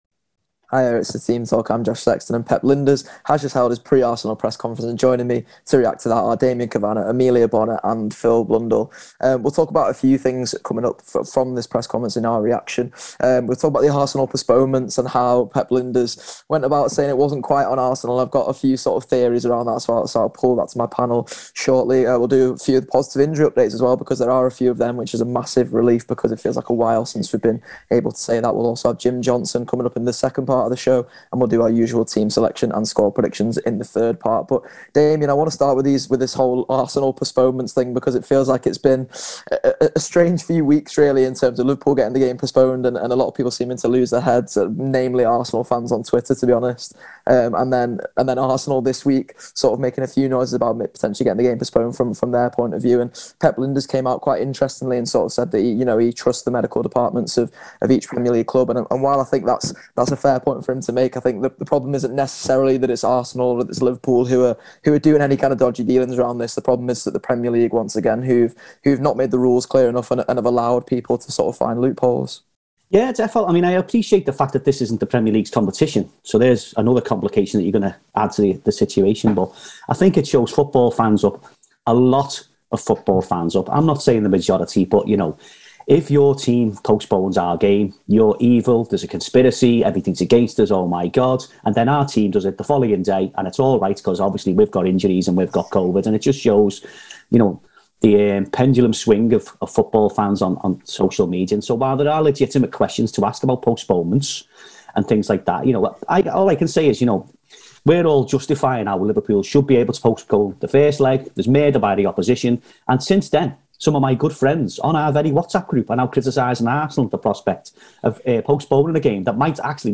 Below is a clip from the show – subscribe for more on Lijnders’ Arsenal v Liverpool press conference…